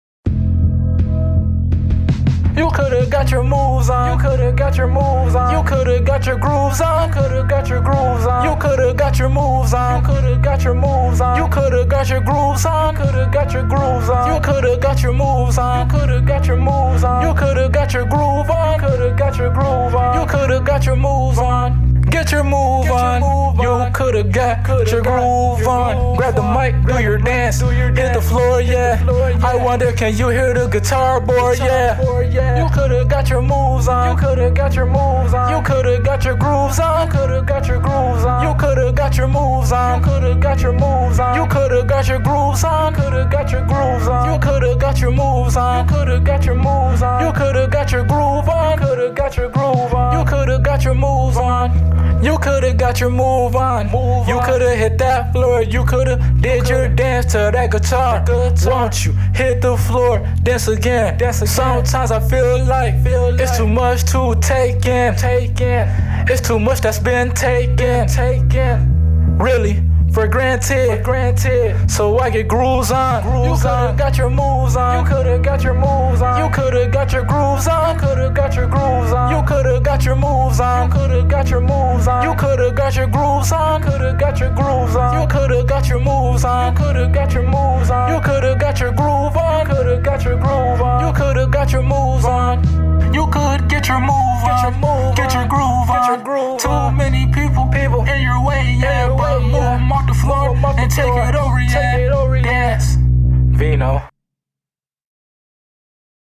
A Alternative Album Mixed With Pop And Rock As Well Enjoy!